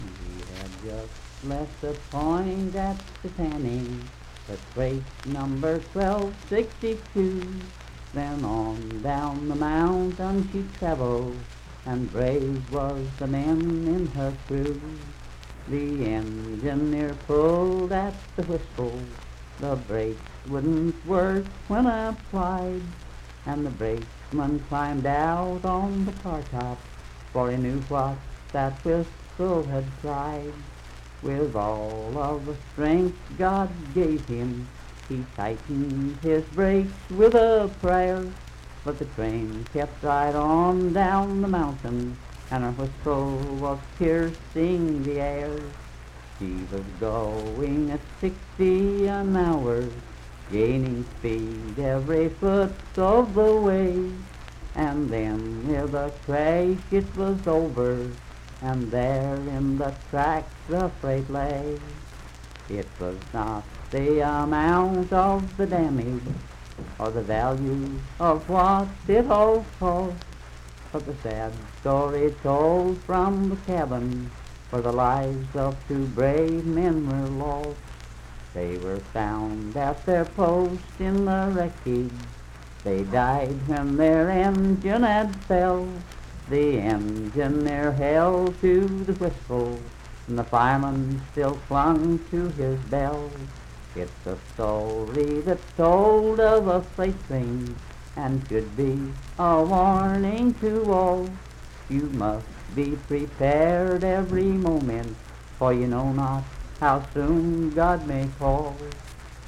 Unaccompanied vocal music
Voice (sung)
Clay County (W. Va.), Clay (W. Va.)